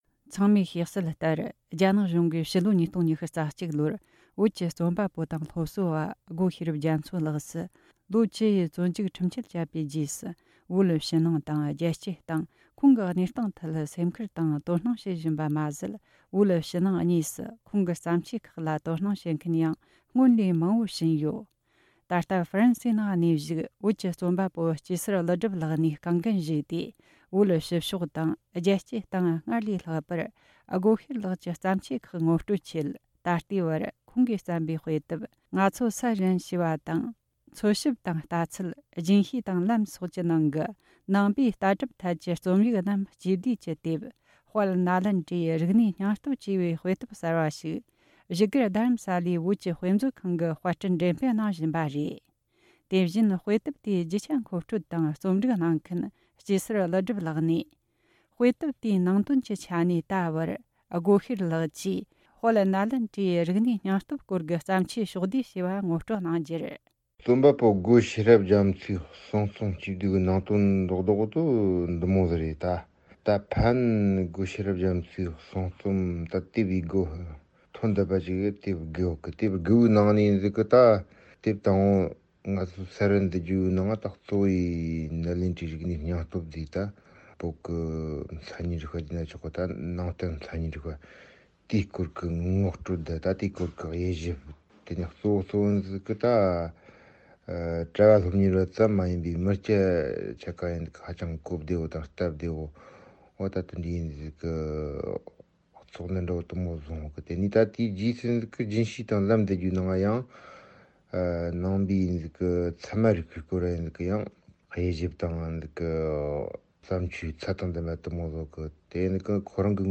བཅར་འདྲི་དང་གནས་ཚུལ་ཕྱོགས་བསྡུས་བྱས་བར་གསན་རོགས་གནོངས།